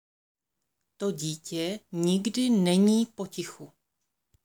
Tady si můžete stáhnout audio na výslovnost DI, TI, NI.